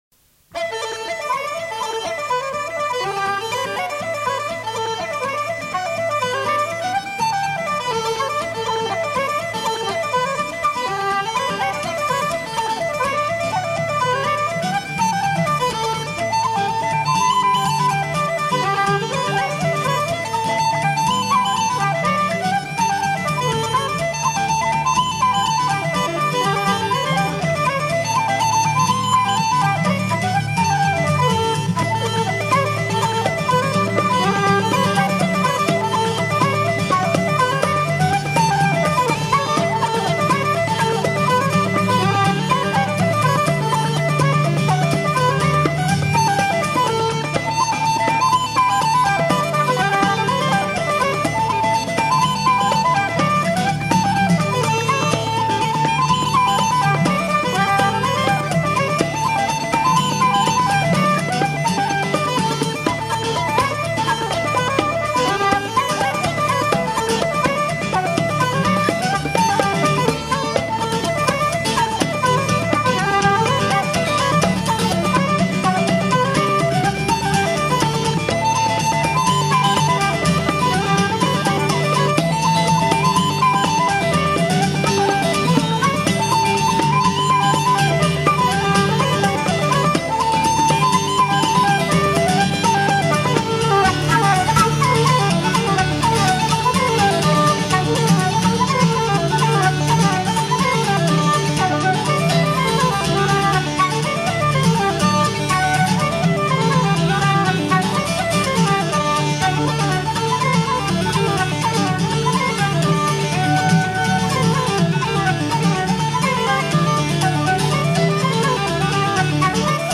trois reels irlandais
Pièce musicale éditée